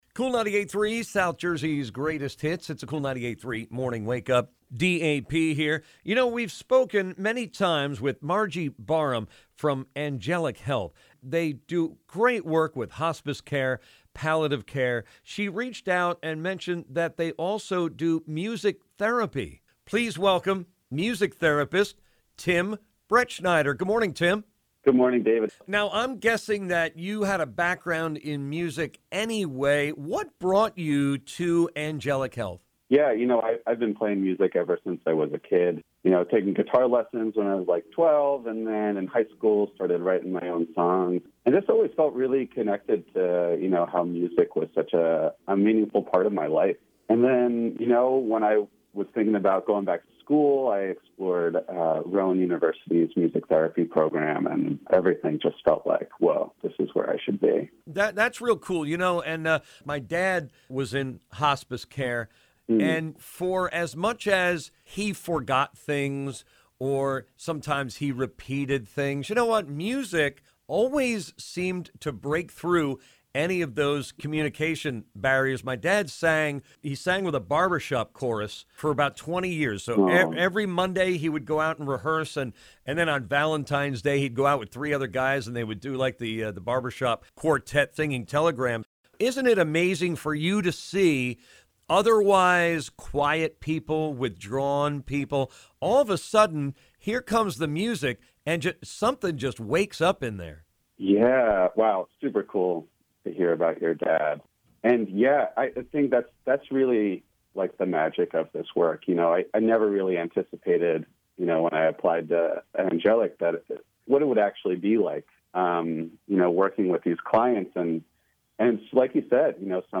At Angelic Health Music Therapy is offered to hospice patients to brighten their mood, lift spirits, reduce pain and stress, and also inspire memories. Listen to this interview and learn more about the wonders of music therapy.